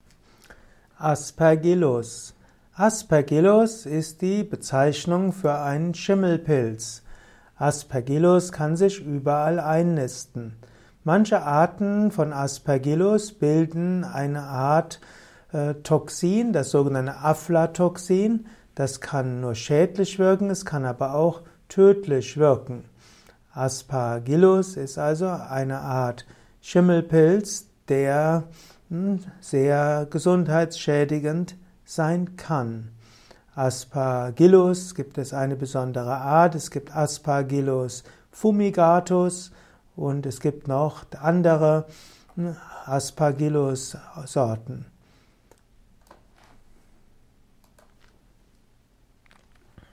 Kompakte Informationen zu Aspergillus in diesem Kurzvortrag